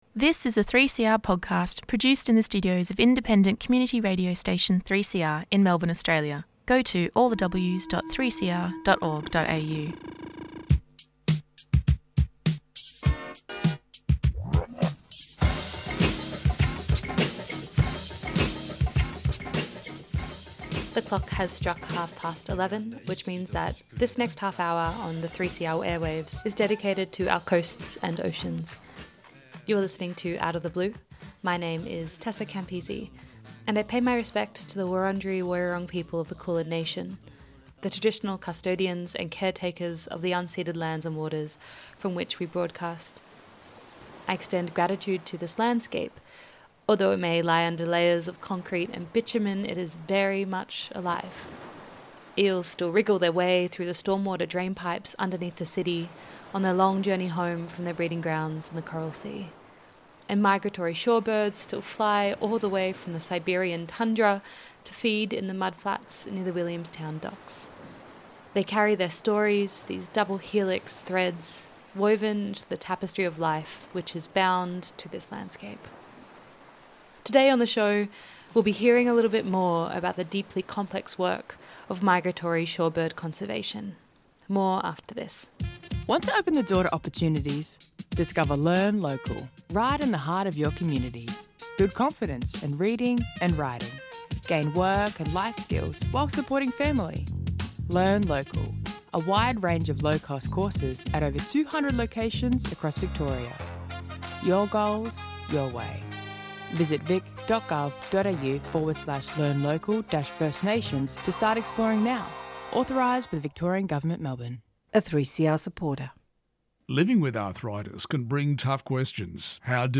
Information about marine and coastal environments. News and interviews with marine scientists, campaigners and conservation workers. Presented by volunteer broadcasters who are passionate about marine environments, both local and across the world.